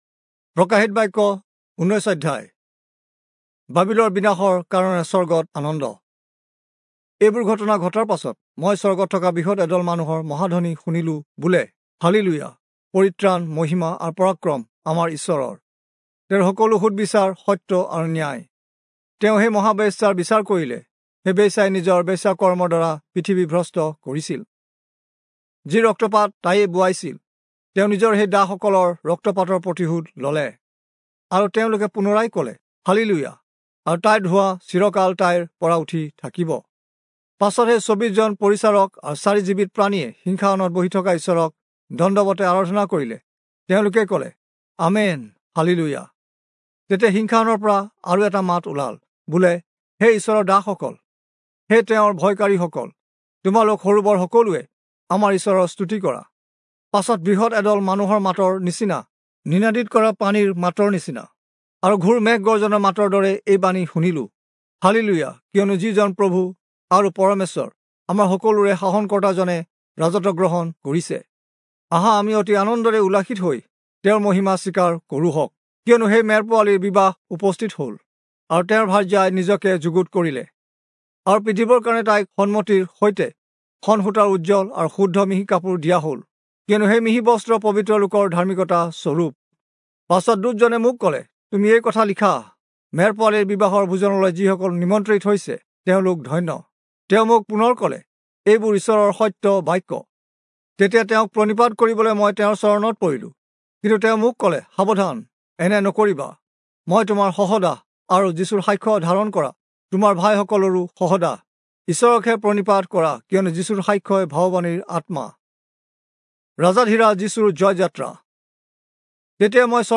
Assamese Audio Bible - Revelation 8 in Mhb bible version